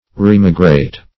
remigrate.mp3